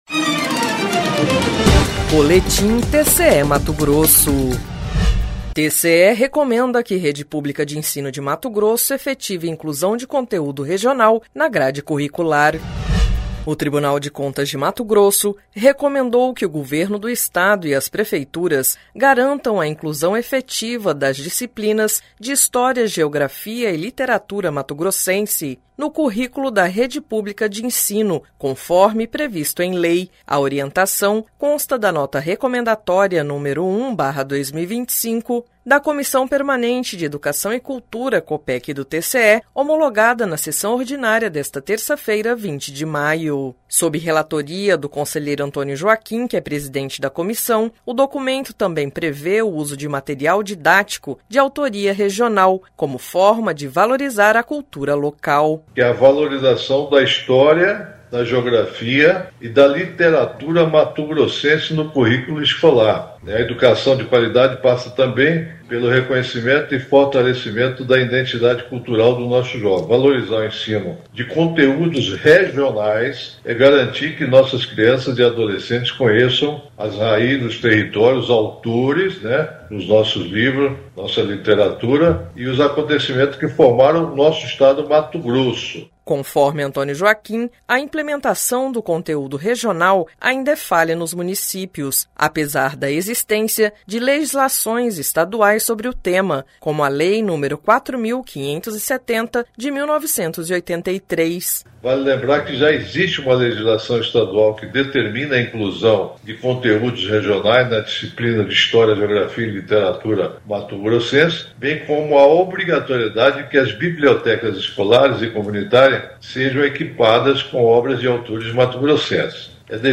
Sonora: Antonio Joaquim – conselheiro do TCE-MT
Sonora: Sérgio Ricardo – conselheiro-presidente do TCE-MT
Sonora: Guilherme Antonio Maluf – conselheiro do TCE-MT
Sonora: Alisson Carvalho de Alencar - procurador-geral de Contas